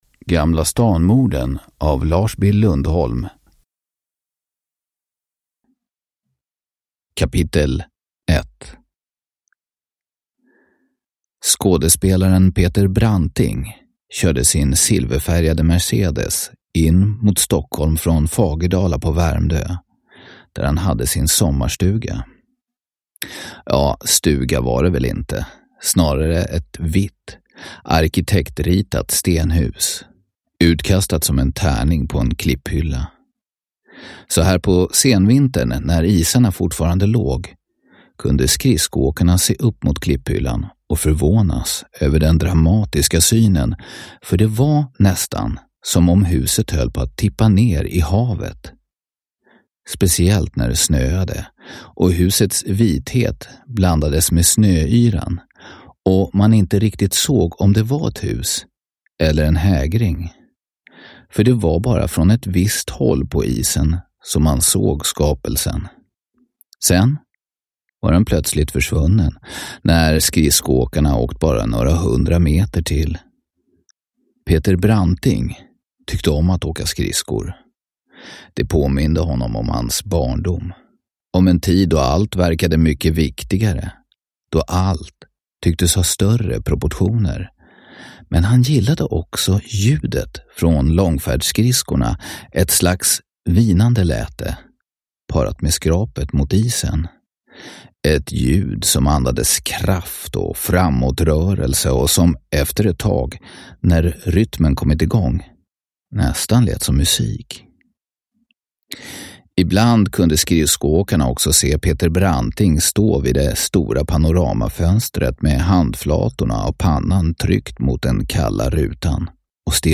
Gamla Stan-morden / Ljudbok